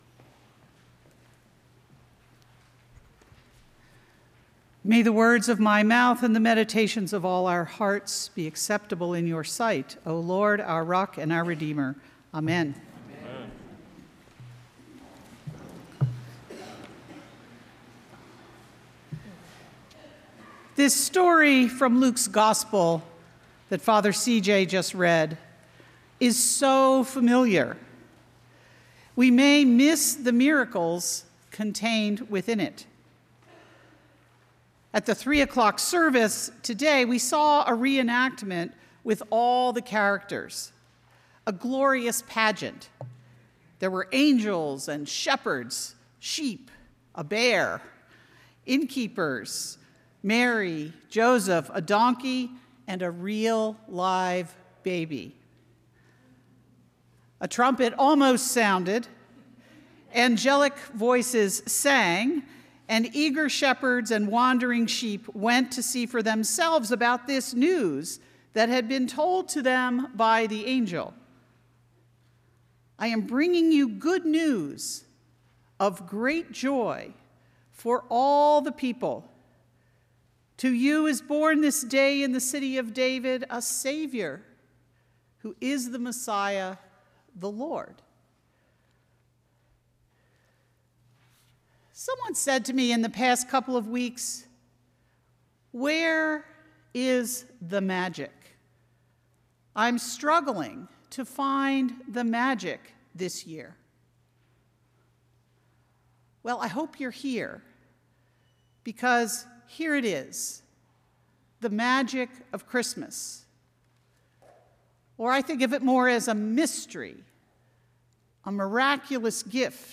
Trinity Episcopal Sermons, Concord, MA